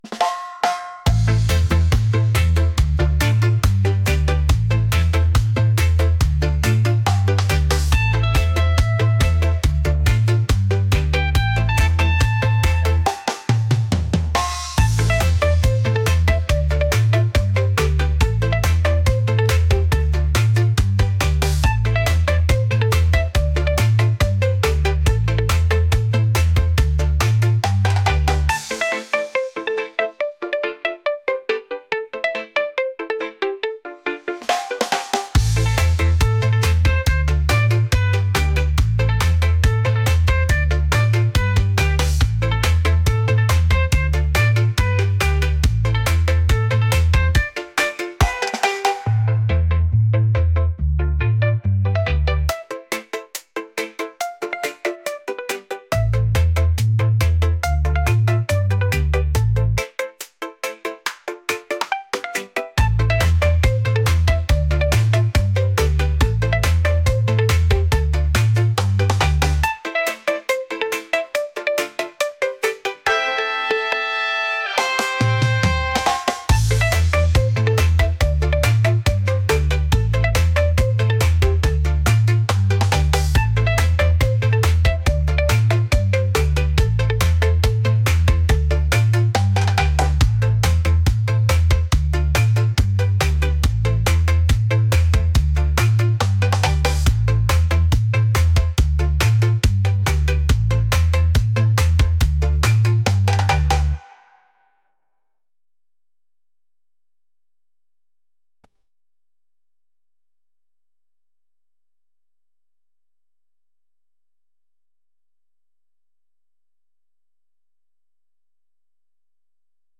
upbeat | reggae | rhythmic